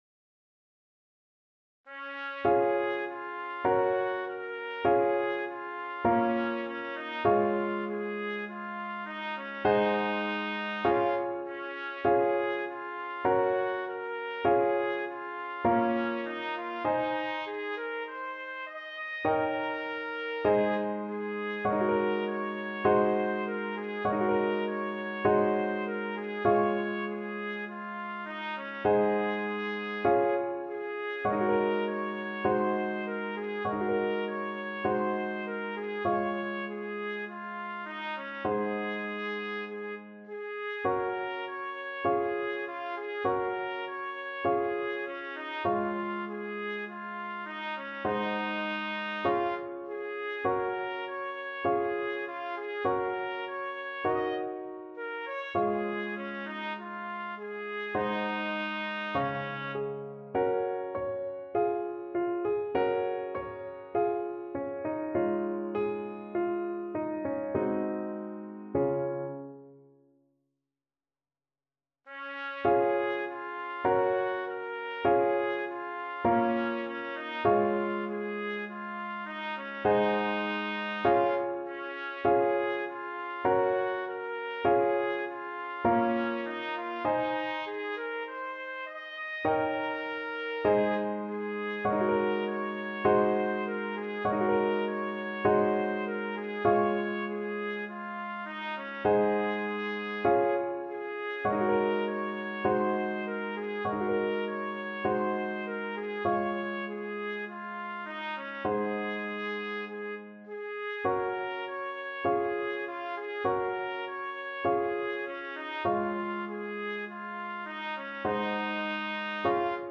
4/4 (View more 4/4 Music)
Moderato
Classical (View more Classical Trumpet Music)